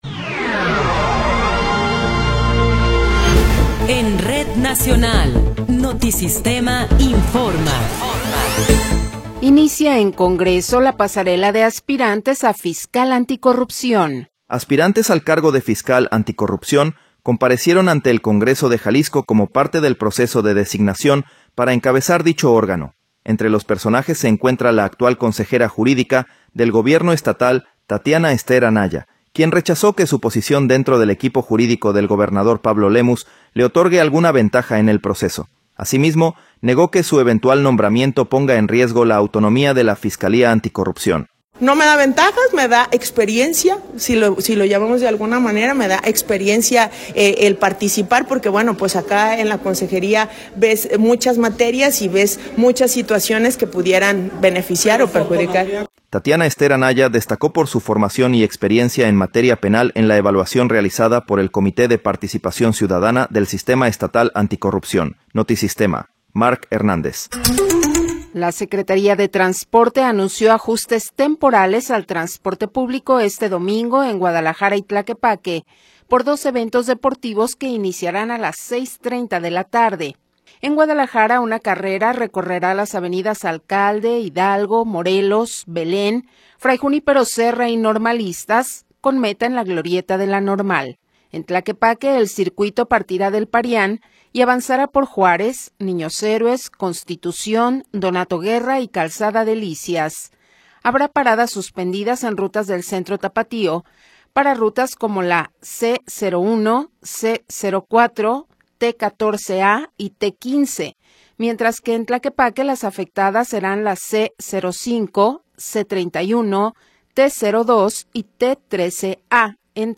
Noticiero 15 hrs. – 16 de Enero de 2026